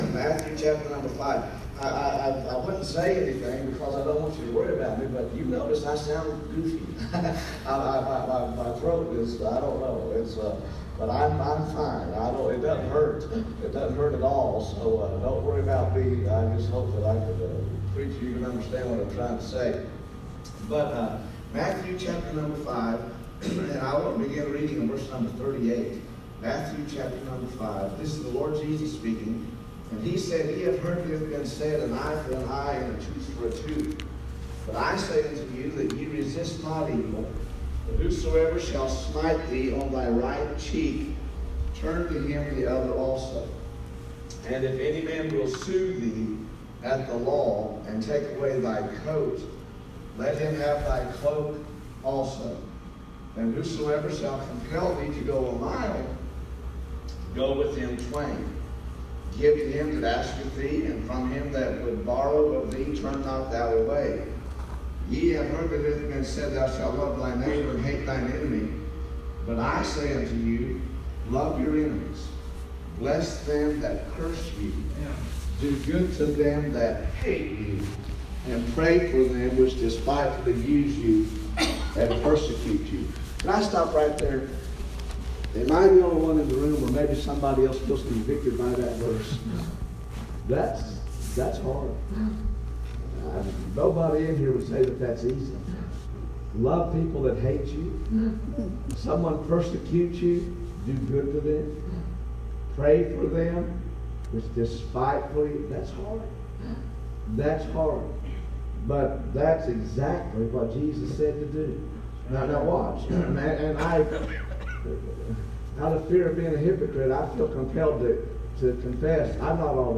Revival